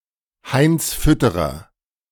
Heinrich Ludwig Fütterer (German pronunciation: [haɪnts ˈfʏtəʁɐ]